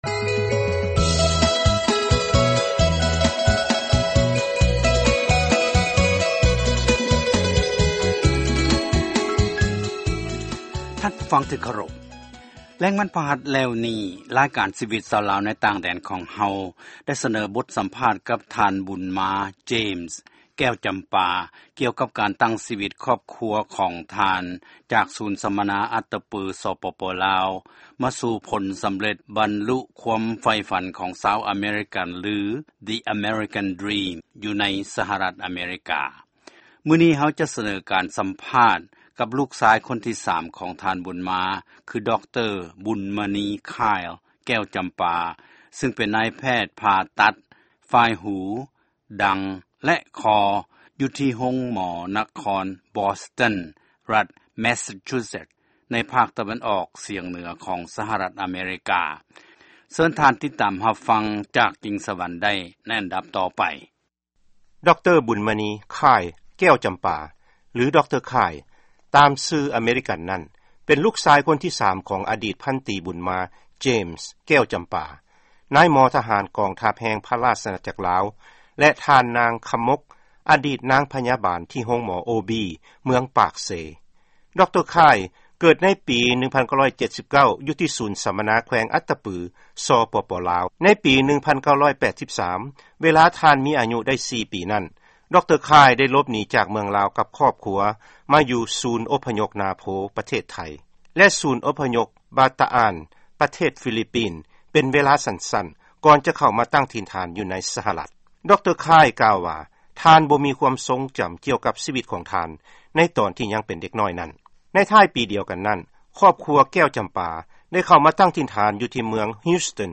ຟັງລາຍການສໍາພາດ